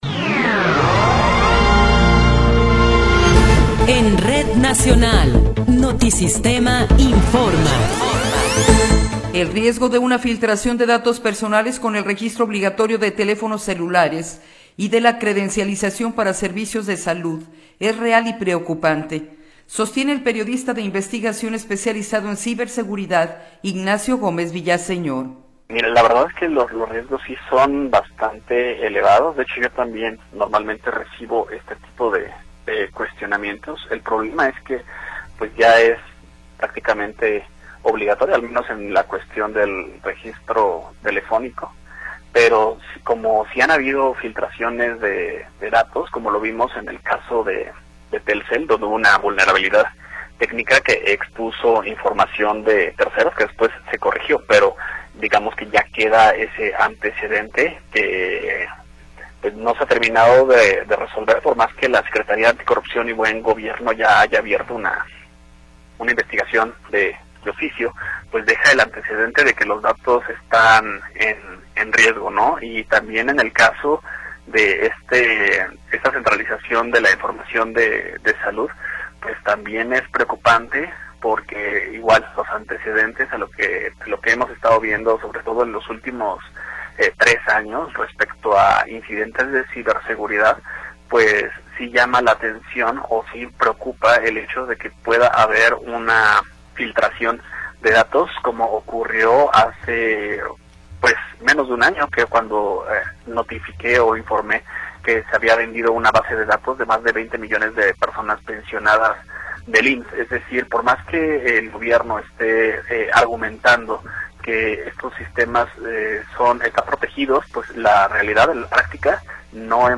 Noticiero 11 hrs. – 25 de Enero de 2026